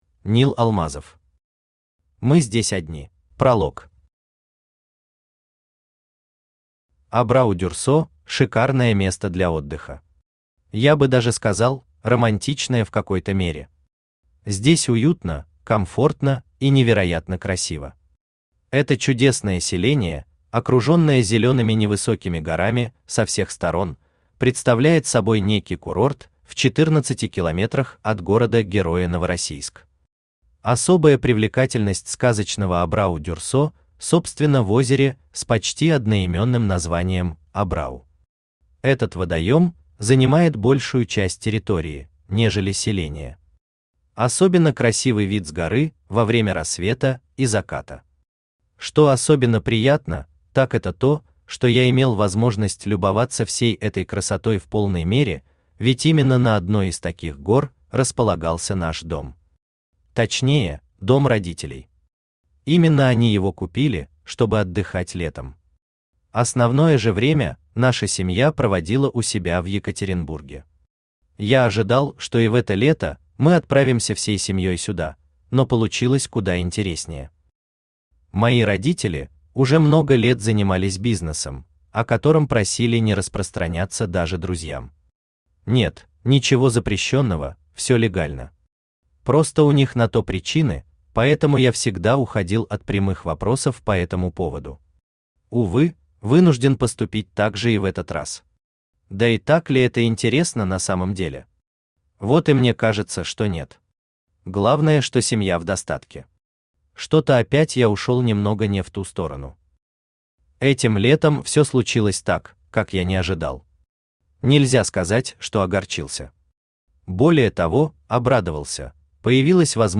Аудиокнига Мы здесь одни…
Автор Нил Алмазов Читает аудиокнигу Авточтец ЛитРес.